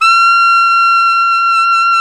SAX ALTOMP0R.wav